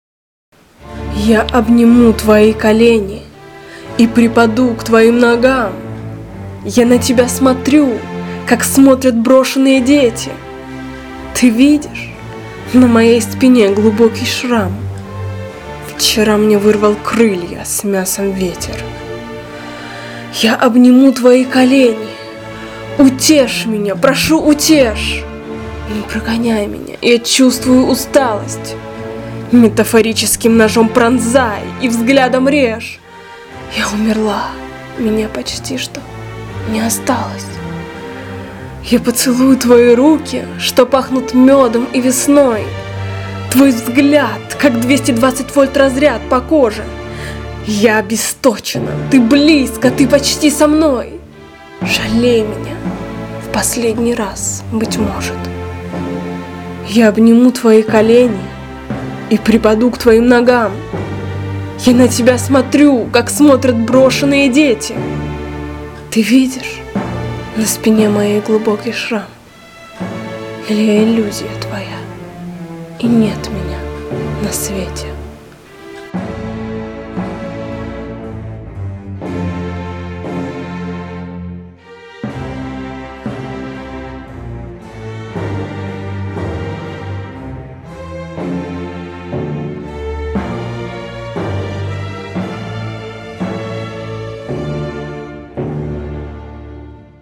и на музыку сильно положено